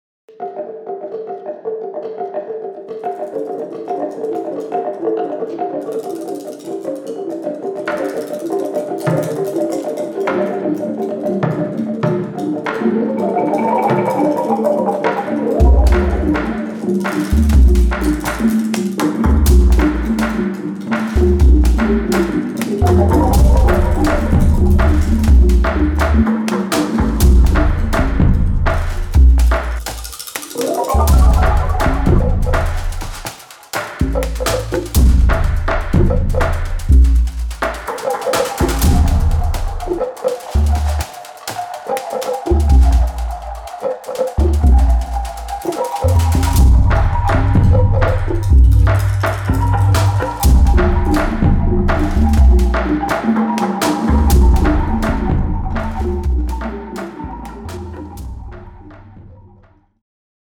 Taiwanese Percussion
who recorded an assortment of traditional Taiwanese percussion instruments, from the pitched Tatuk (Taiwanese xylophone) and Tutu Djima (bamboo tubes), to untuned drums like the Kuku and the Tatuk Ubung.
Tatuk (Taiwanese Xylophone), Tatuk Ubung, Tatu Djima, Tatu Btakan, Kuku